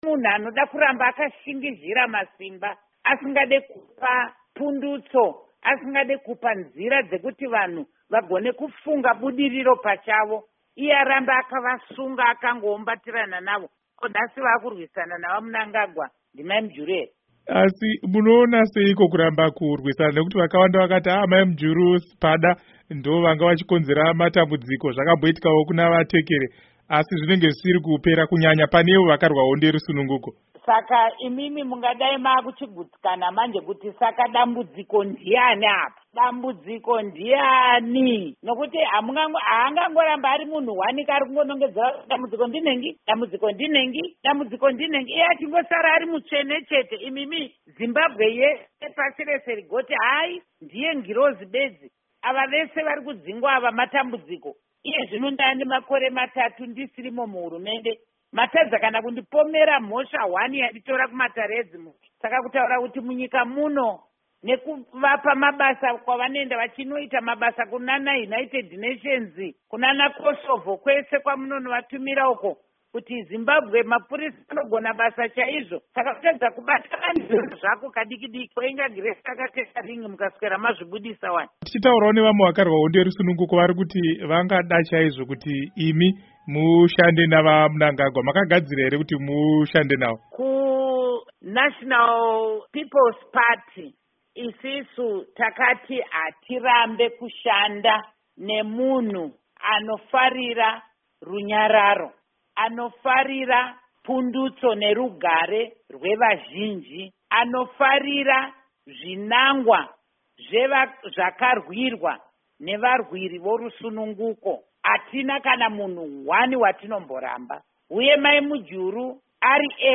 Hurukuro naAmai Joice Mujuru